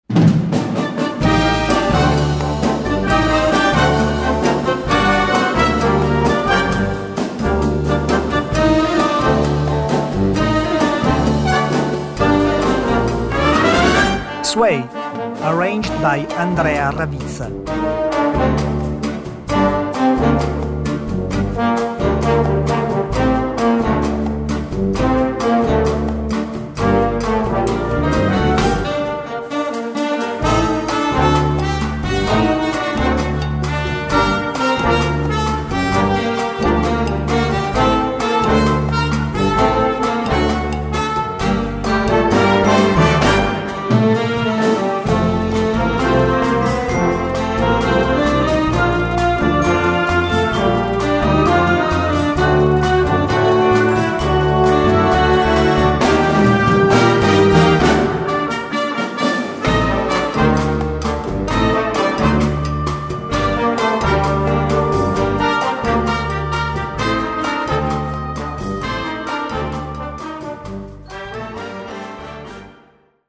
Gattung: Moderner Einzeltitel
Besetzung: Blasorchester